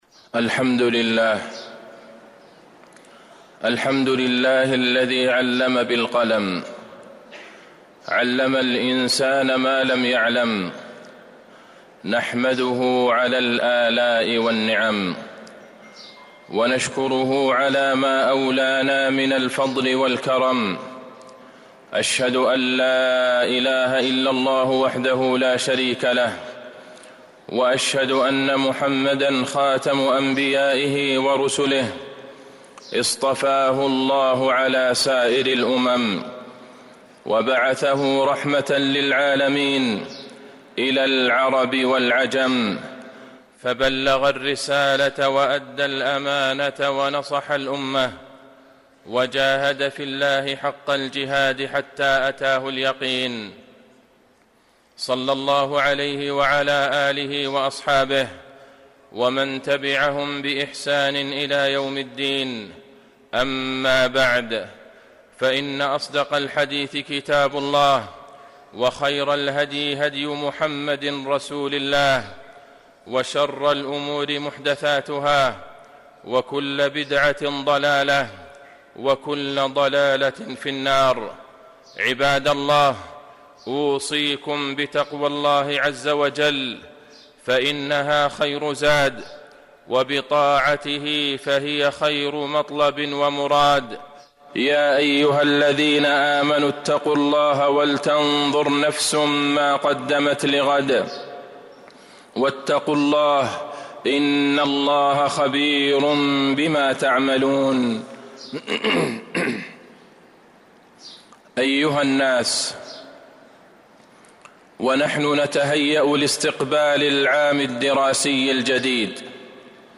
المدينة: عند بدء العام الدراسي الجديد - عبد الله بن عبد الرحمن البعيجان (صوت - جودة عالية